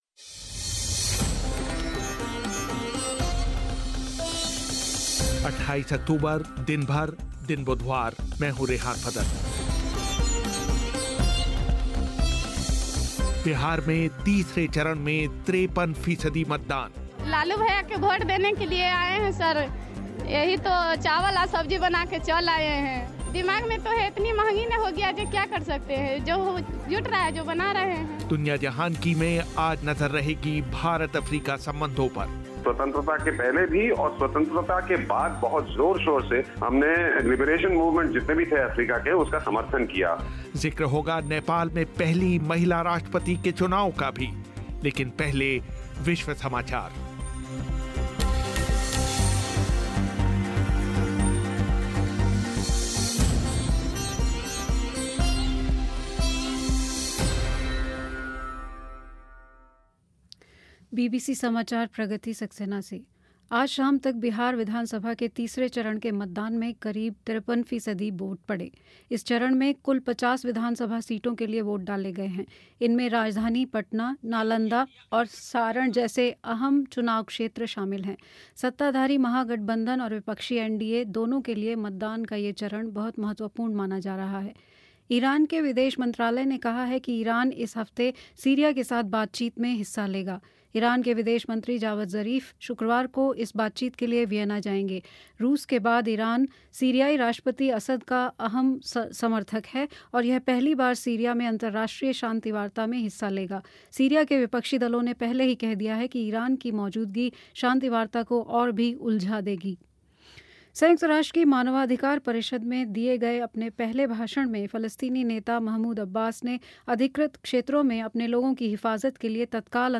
बात करेंगे बीजेपी नेता और वित्त मंत्री अरुण जेटली से